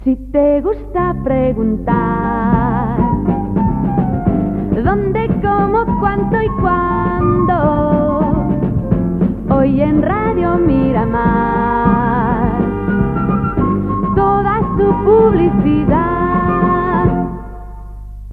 Promoció cantada de la publicitat a l'emissora